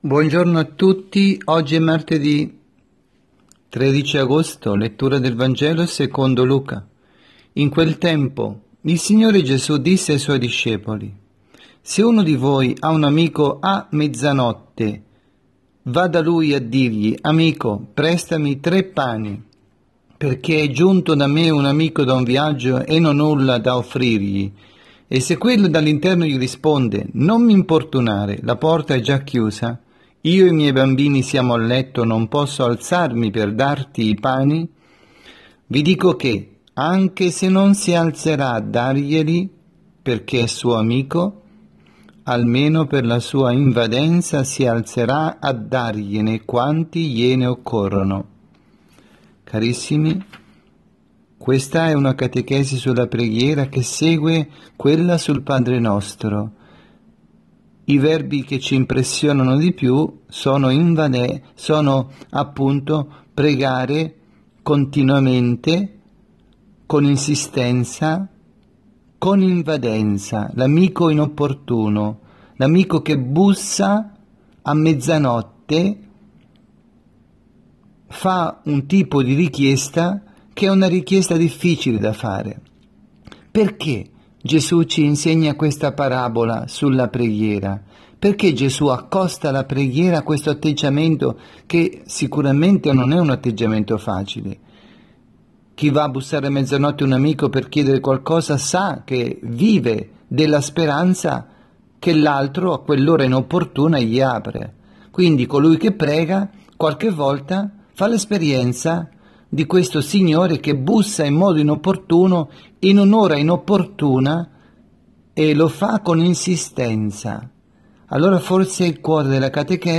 2019-08-13_Martedi_pMG_Omelia_La_Thuile